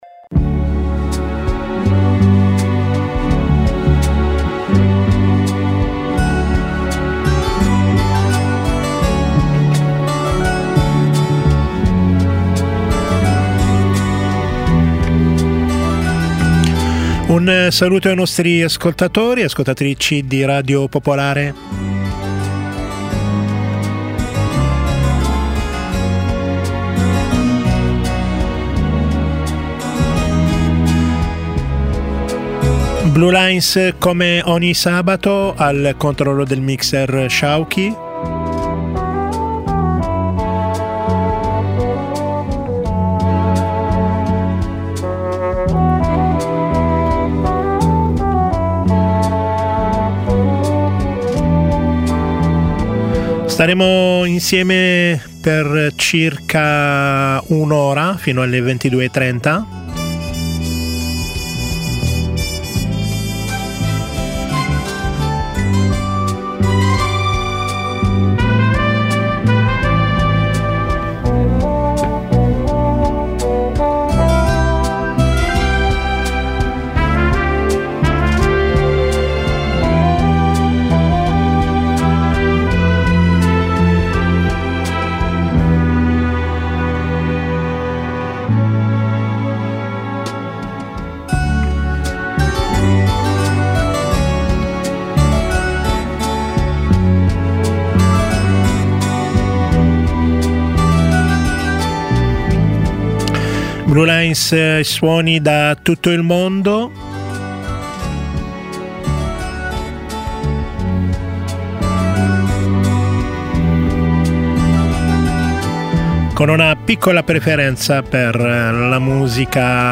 Conduzione musicale